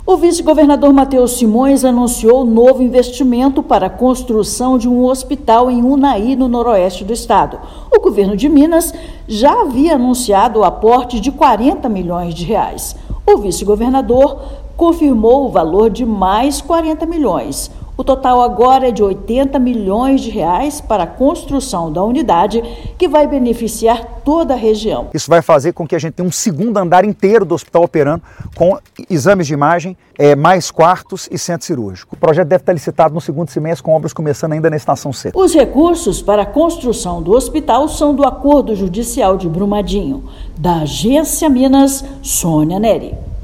Nova unidade de saúde vai beneficiar população da região Noroeste do estado. Ouça matéria de rádio.